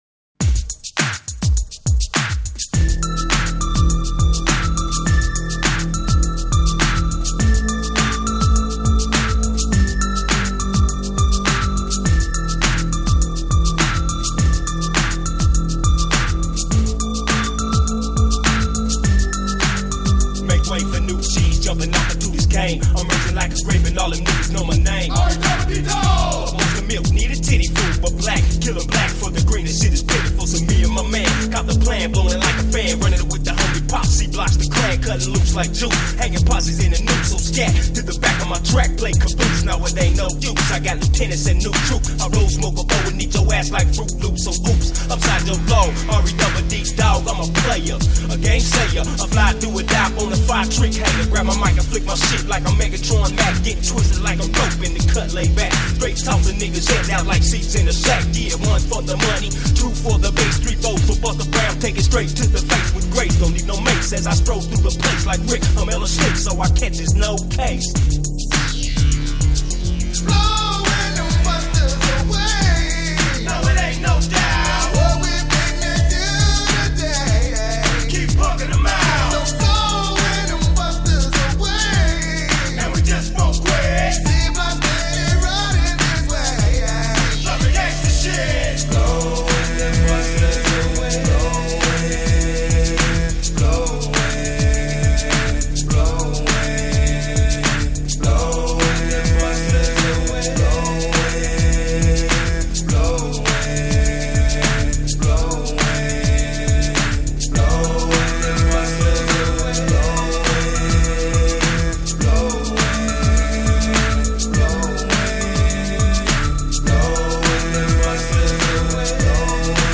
Жанр: Rap, Hip Hop